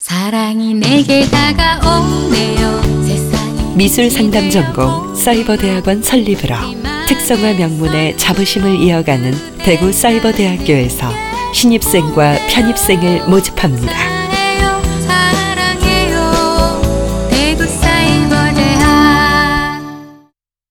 2012학년도 학부모집 라디오 듣기 다운로드
RadioCM_2011_입시모집.wav